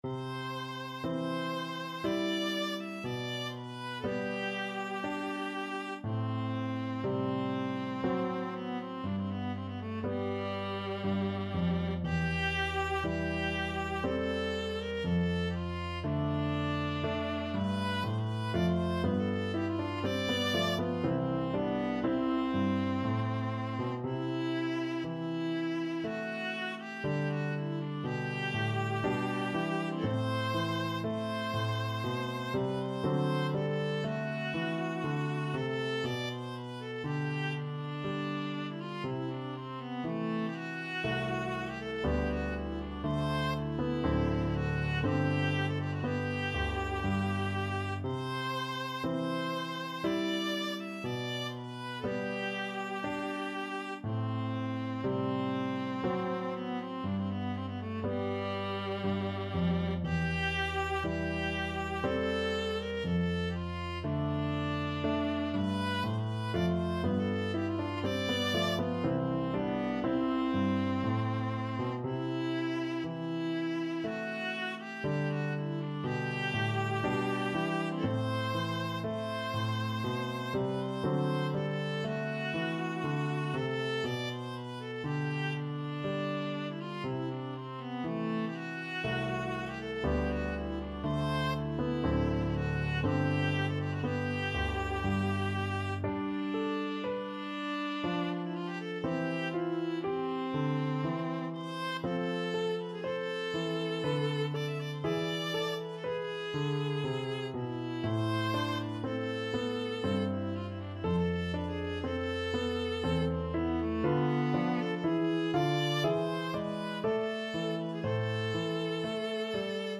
Classical Bach, Johann Sebastian Aria from Goldberg Variations Viola version
Viola
This is an arrangement of the main aria for solo viola.
3/4 (View more 3/4 Music)
C major (Sounding Pitch) (View more C major Music for Viola )
Classical (View more Classical Viola Music)